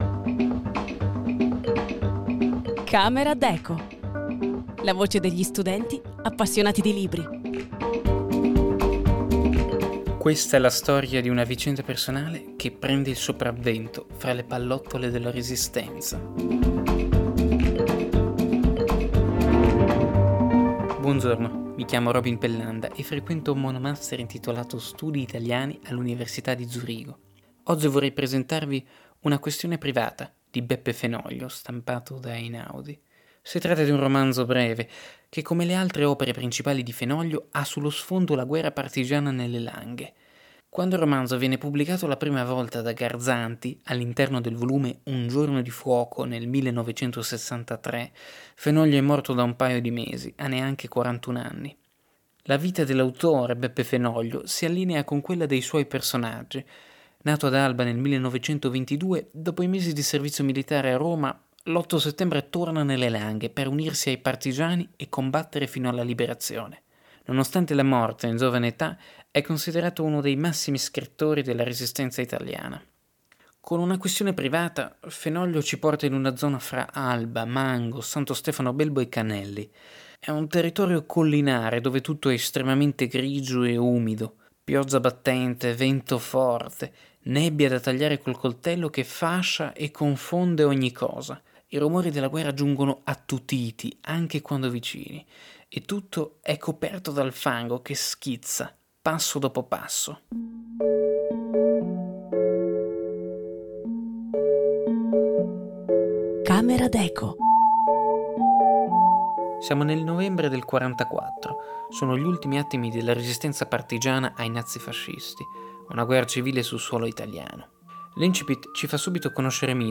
La voce degli studenti appassionati di libri